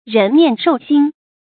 注音：ㄖㄣˊ ㄇㄧㄢˋ ㄕㄡˋ ㄒㄧㄣ
讀音讀法：